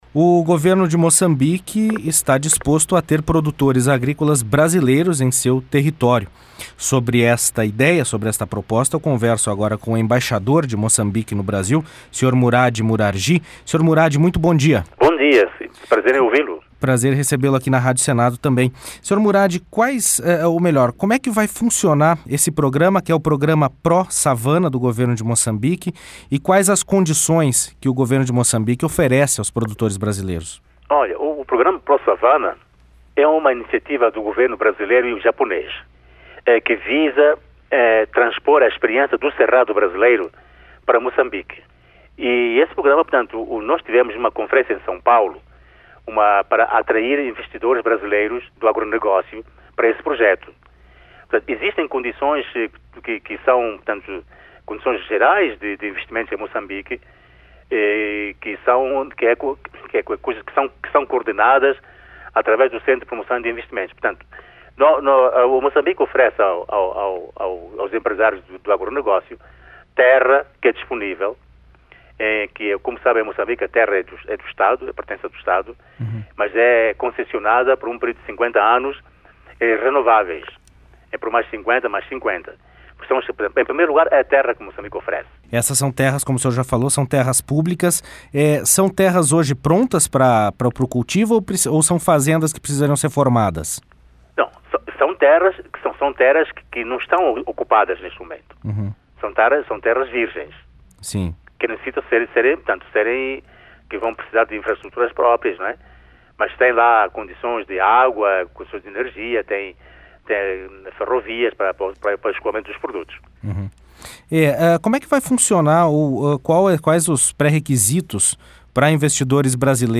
Entrevista com o embaixador de Moçambique Murade Isaac Miguigy Murargy.